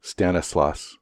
Stanislaus County (/ˈstænɪslɔː(s)/
En-us-stanislaus.ogg.mp3